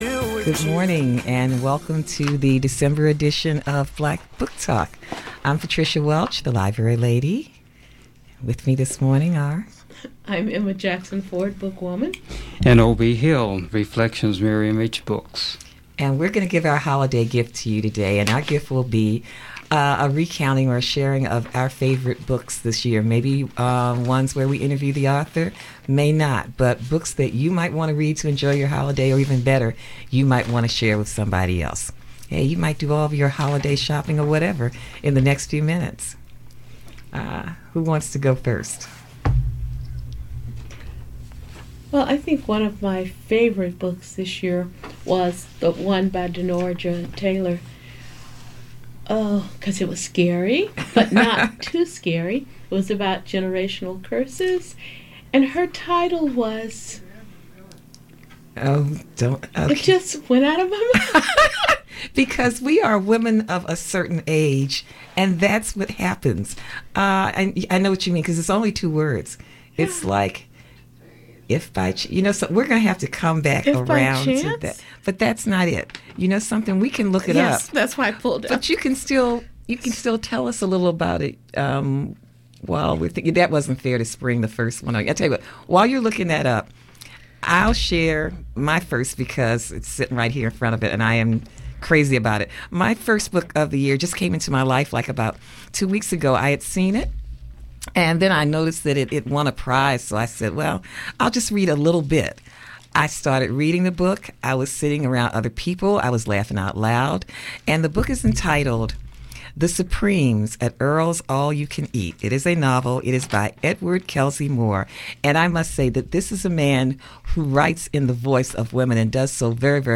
A Conversation with Dr. Maya Angelou about her book, Mom & Me & Mom